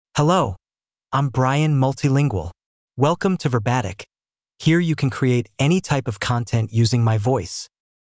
MaleEnglish (United States)
Brian MultilingualMale English AI voice
Brian Multilingual is a male AI voice for English (United States).
Voice sample
Brian Multilingual delivers clear pronunciation with authentic United States English intonation, making your content sound professionally produced.